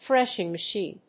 アクセント・音節thrésh・ing machìne